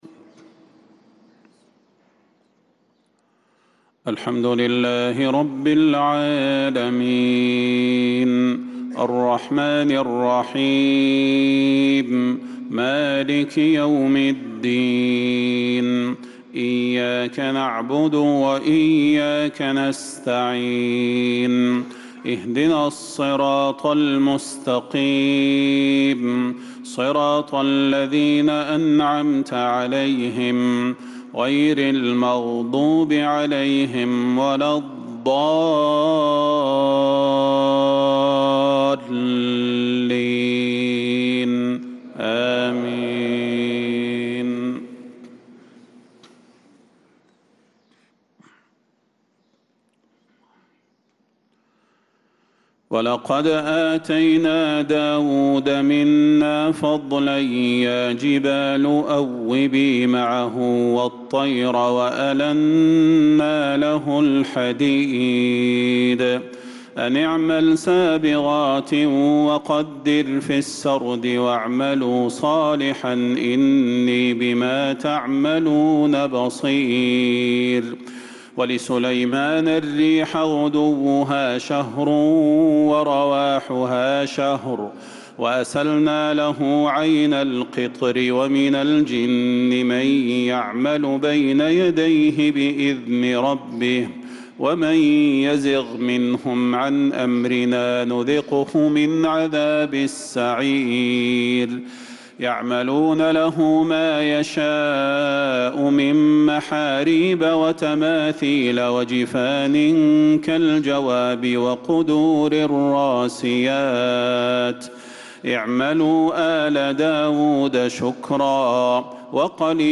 صلاة الفجر للقارئ صلاح البدير 25 جمادي الأول 1446 هـ
تِلَاوَات الْحَرَمَيْن .